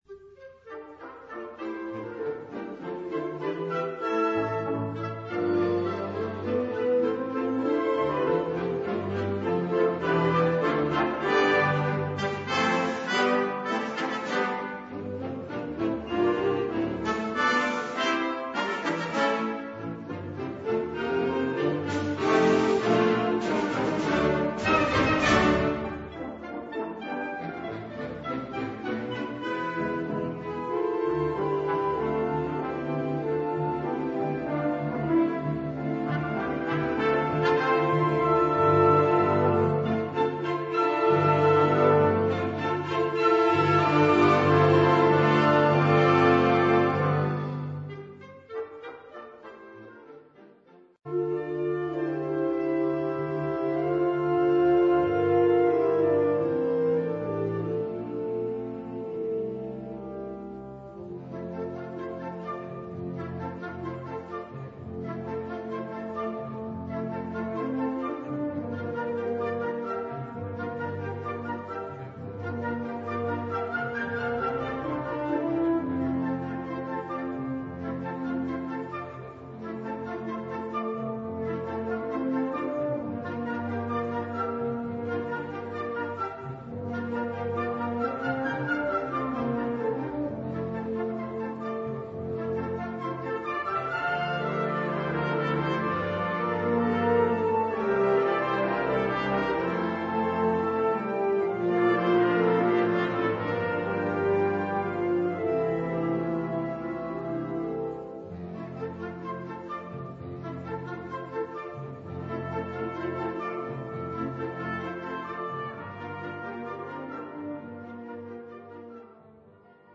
Catégorie Harmonie/Fanfare/Brass-band
Sous-catégorie Suite
Instrumentation Ha (orchestre d'harmonie)
I. Les Narbantons (Giocoso)
II. Les Jarrons (Grazioso)
III. Les Serpentieres (Festivo)
IV. Dessus des Golardes (Vivace)